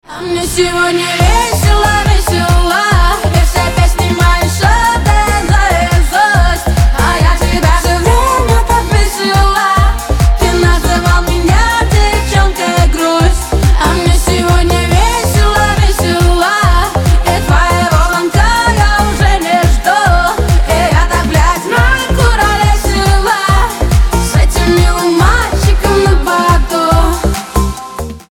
• Качество: 320, Stereo
зажигательные
заводные
женский голос